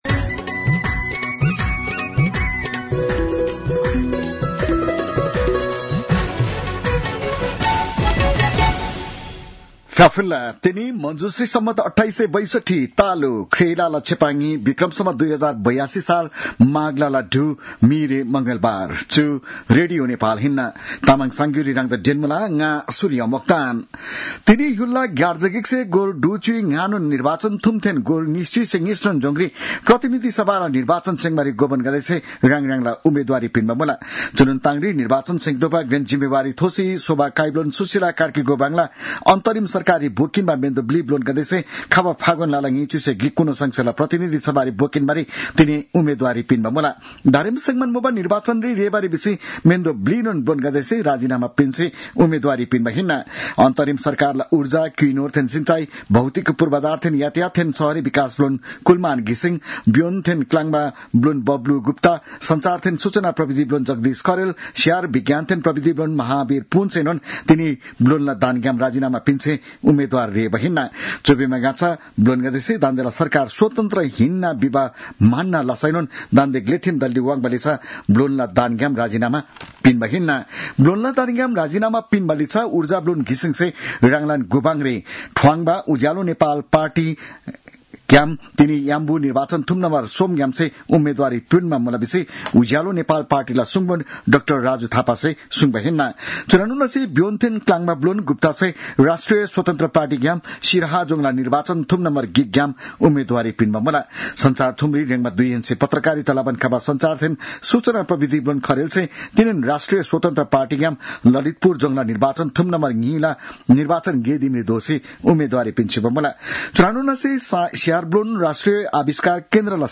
तामाङ भाषाको समाचार : ६ माघ , २०८२